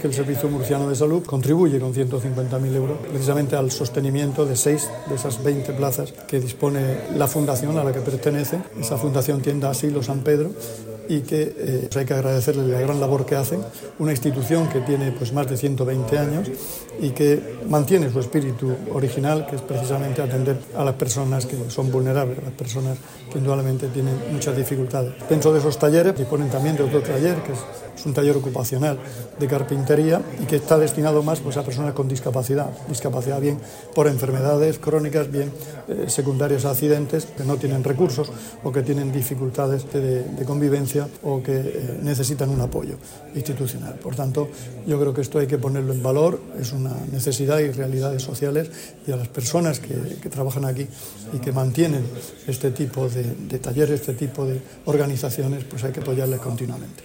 Sonido/ Declaraciones del consejero de Salud, Juan José Pedreño, en su visita a la Fundación Tienda Asilo de San Pedro para la acogida de enfermos sin recursos [mp3].